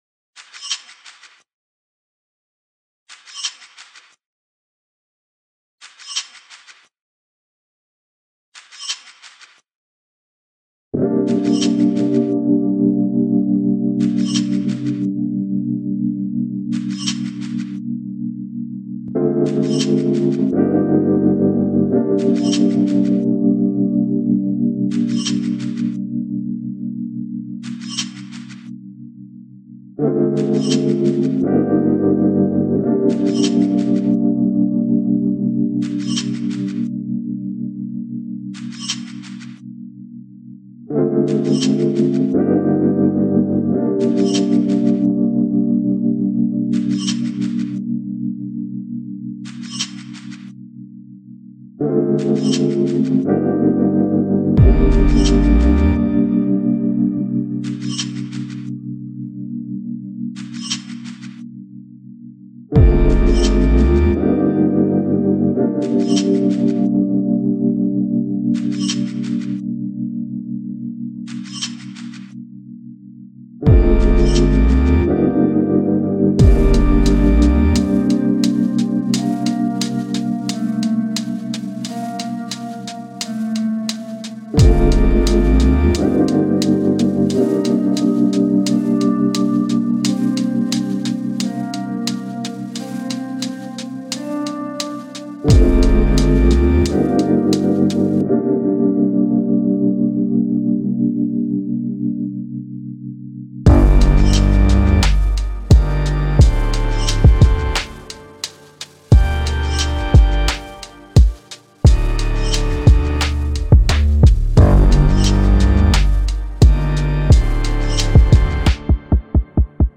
On a décidé de faire des musiques ambiantes qui pourraient coller avec un court métrage ou bien un film.